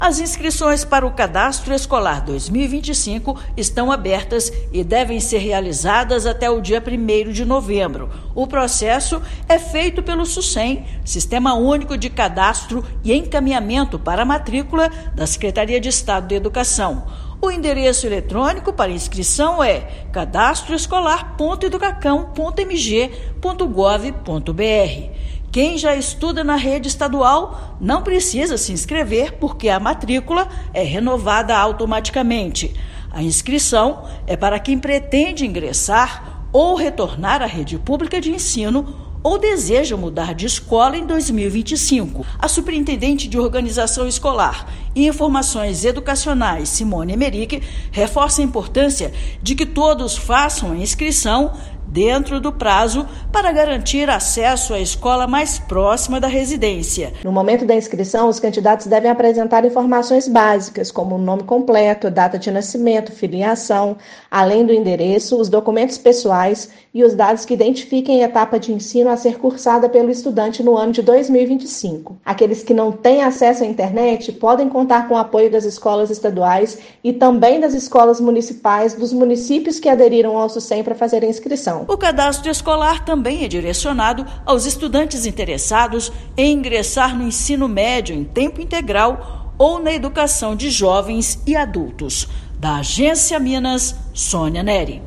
Interessados devem se inscrever exclusivamente pela internet, por meio do Sucem; renovação da matrícula para quem já estuda na rede estadual é automática. Ouça matéria de rádio.